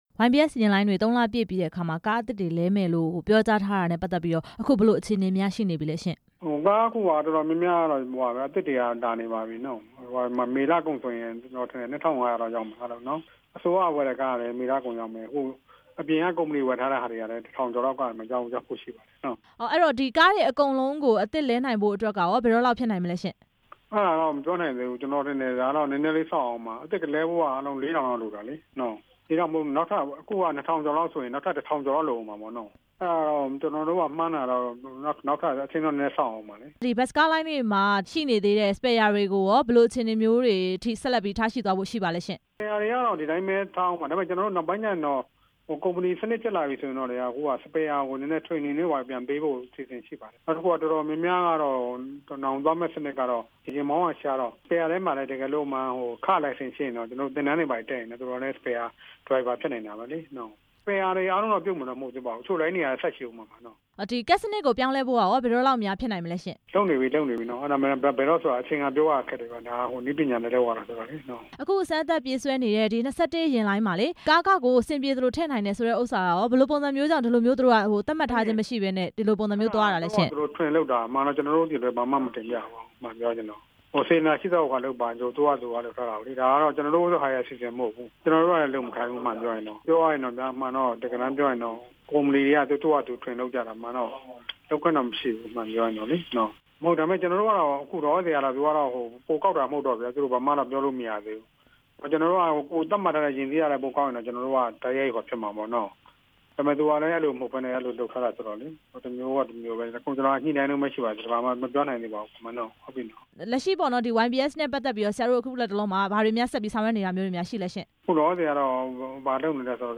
YBS ယာဉ် အစီးရေ ၂၀၀၀ ကျော် ကားသစ်လဲလှယ်ဖို့ မေးမြန်းချက်